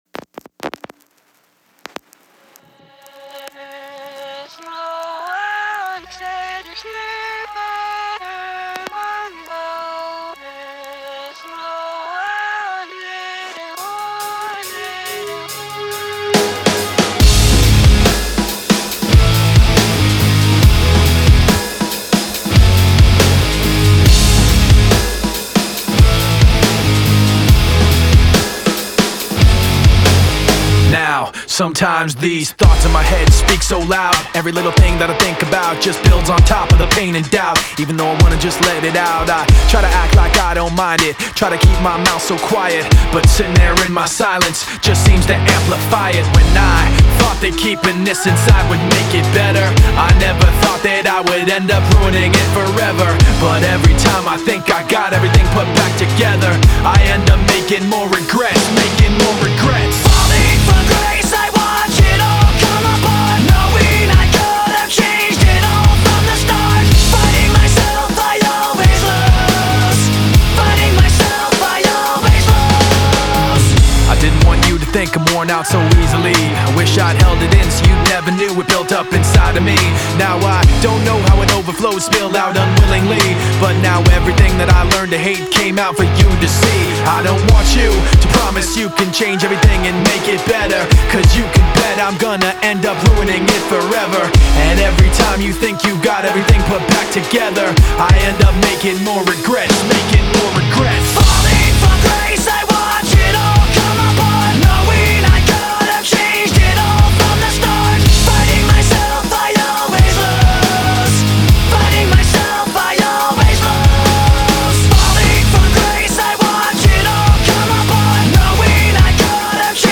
Genres:nu metal